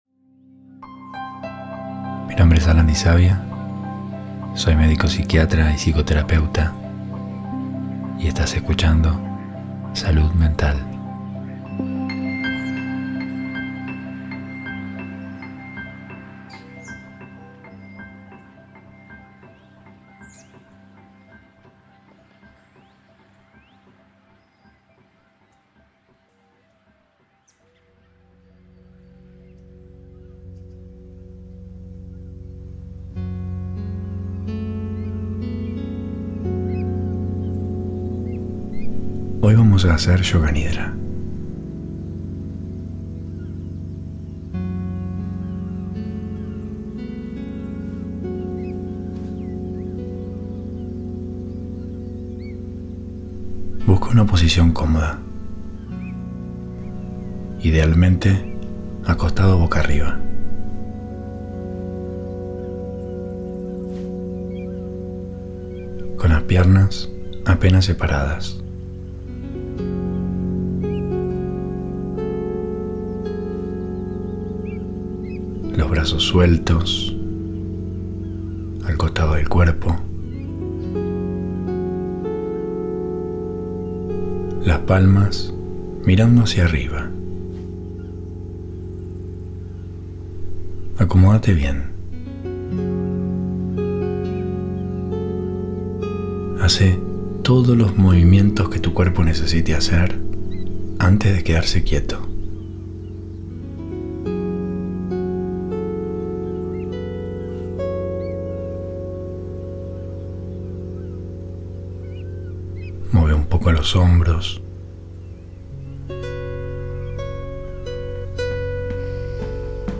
(Primeros 10 minutos) Yoga Nidra para Dormir sin Rumiación
(Se recomienda escuchar con auriculares.)